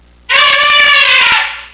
Elephant 3
elephant-3.wav